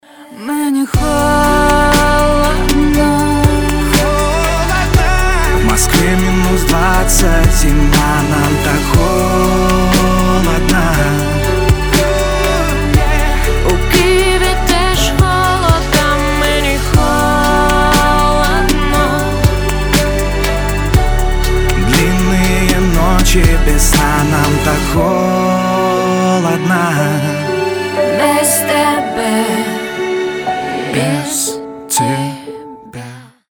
• Качество: 320, Stereo
красивые
дуэт
медленные